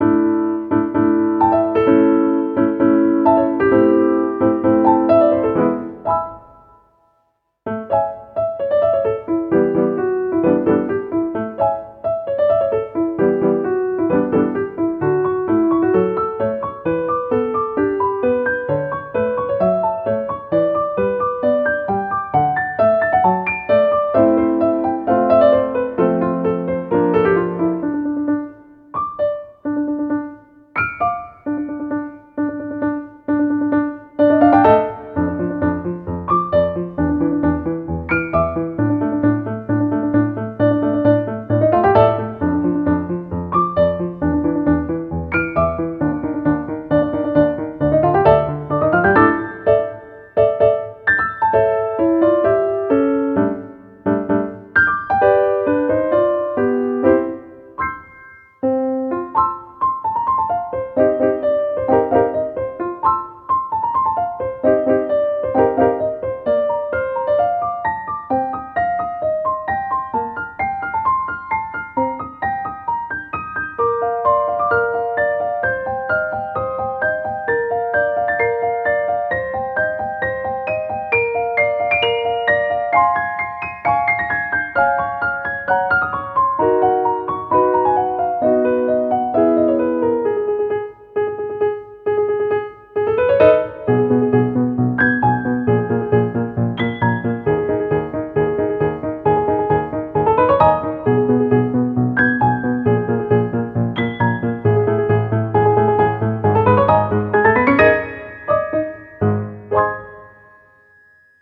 ogg(R) - 軽やか お洒落 不思議
軽やかに飛び回る妖精のように。